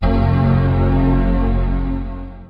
Moderne Mac start-op lyd.
Akkorden er transponeret til F#-dur (i en stemning lidt under de normale 440 Hz).
En flot, bred akkord som virkelig stemmer sindet til en harmonisk og kreativ dag med alt hvad computeren måtte bringe af excel-, word-, photoshop- og emailudfordringer.